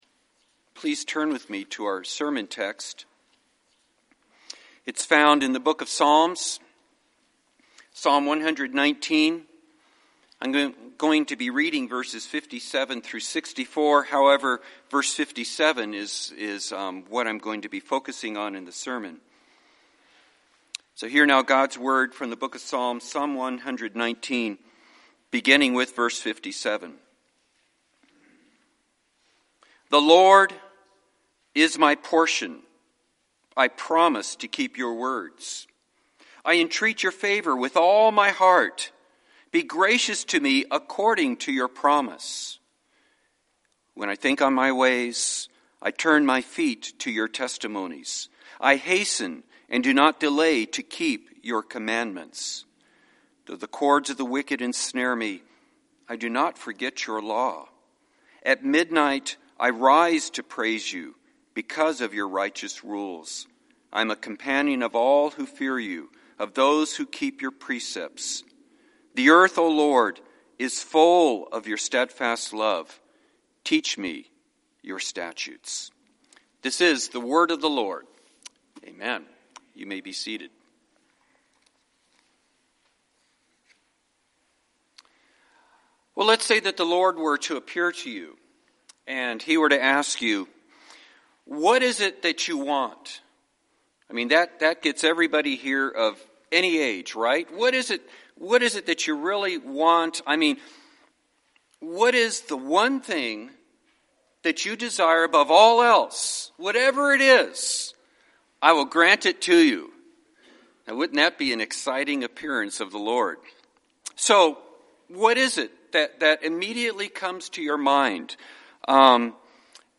(Guest preacher)